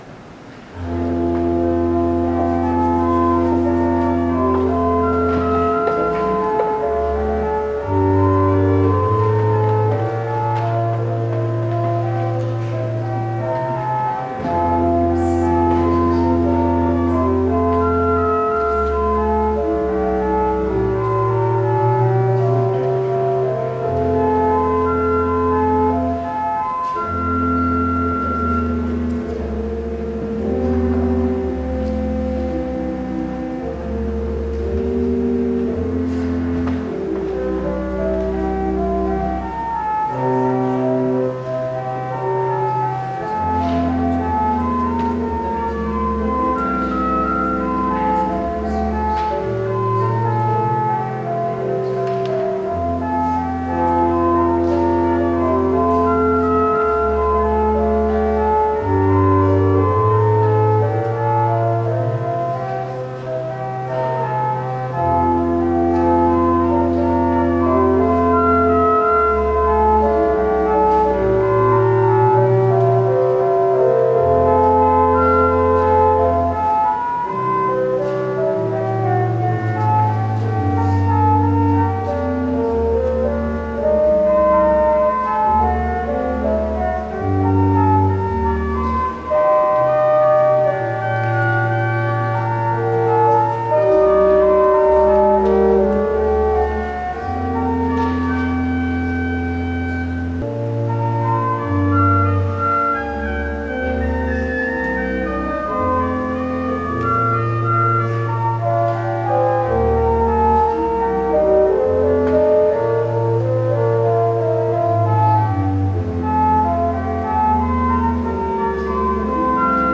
Worship Service December 1, 2019 | First Baptist Church, Malden, Massachusetts
Sermon